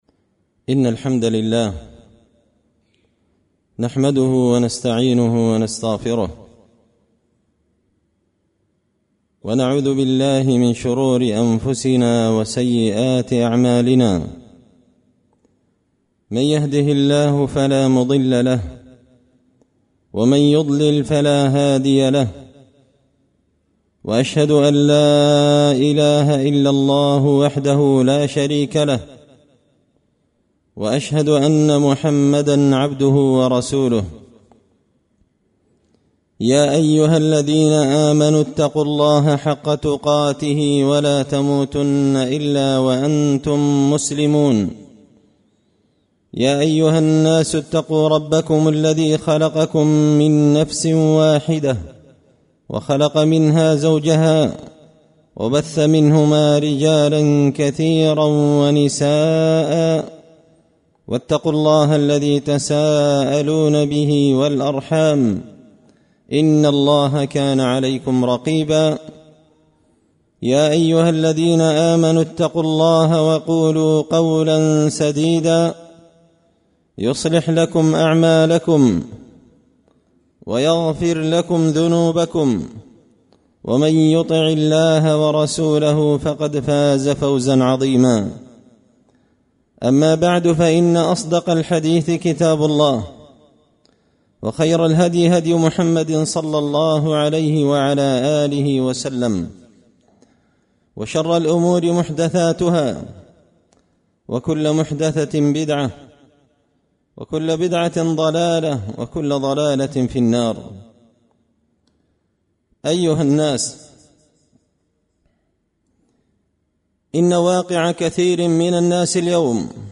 خطبة جمعة بعنوان – التكاثر الممدوح
دار الحديث بمسجد الفرقان ـ قشن ـ المهرة ـ اليمن